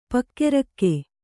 ♪ pakke rakke